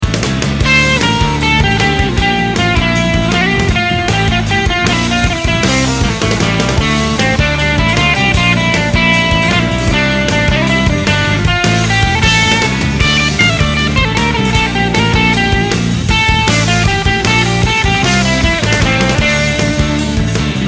It's sort of twangy but that may be more of the guitar than the pickup.
The fourth clip is of the GFS Vintage '59 bridge using the 2x12 Crunch patch playing along to a surf-ish jam track.